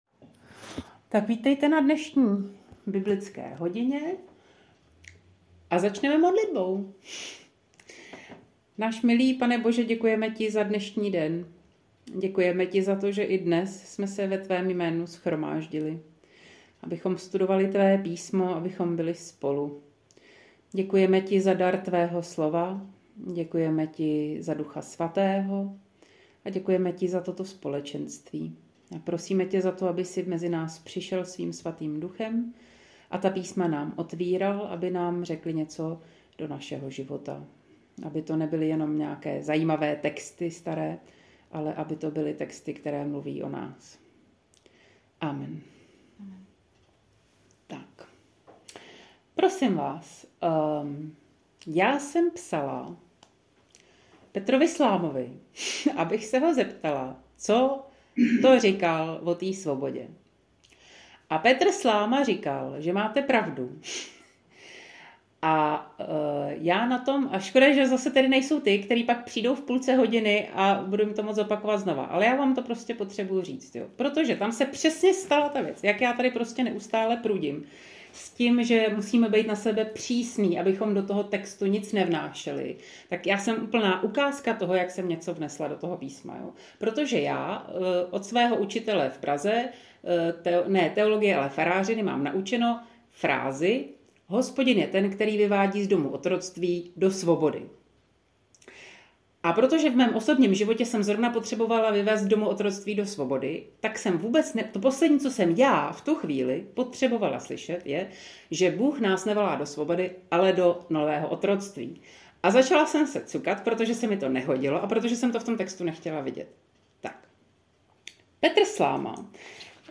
Záznamy z biblické hodiny